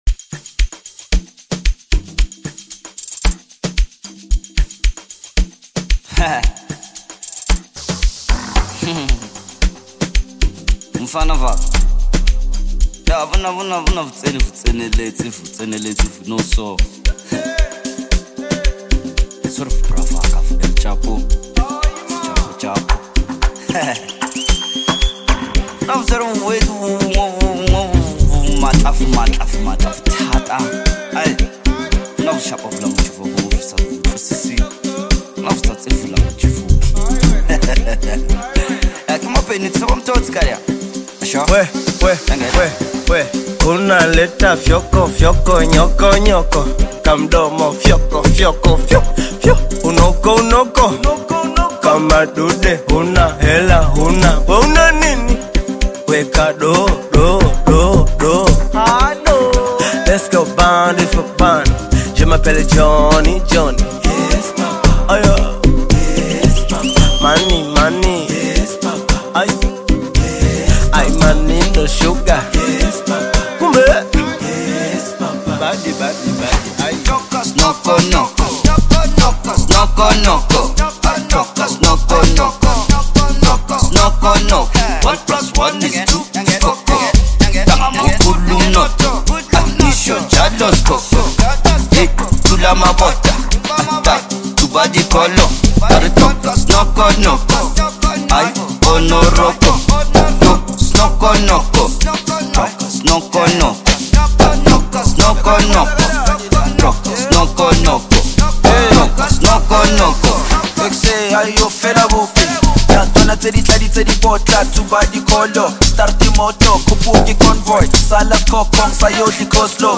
soul-stirring song